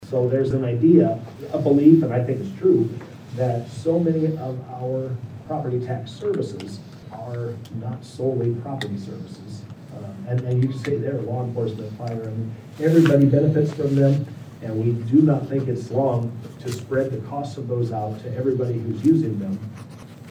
Pictured: Rep. Craig Williams (left) and Sen. Jason Schultz (right) speak during the Jan. 24 Legislative Forum at St. Anthony Regional Hospital